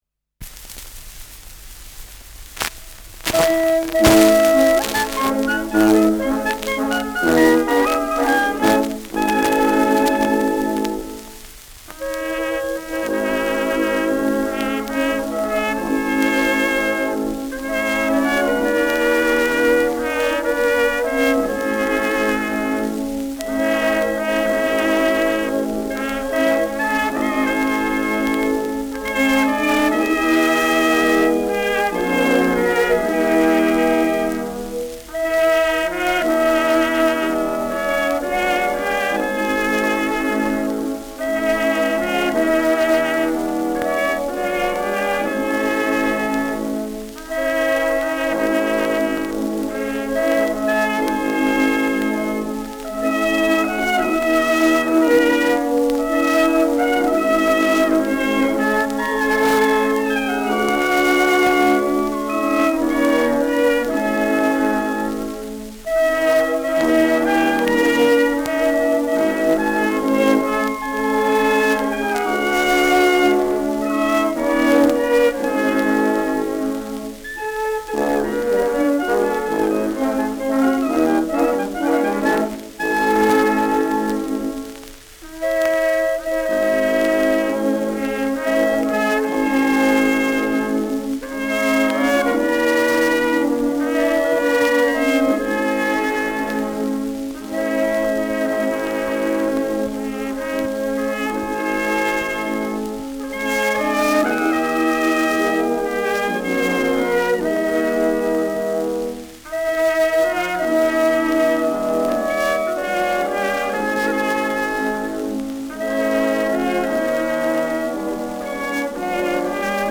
Schellackplatte
Knacken zu Beginn : leichtes Knistern : leichtes Rauschen : abgespielt : leiert